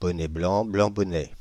ÄäntäminenFrance (Île-de-France):
• IPA: /blã bɔ.nɛ bɔ.nɛ blã/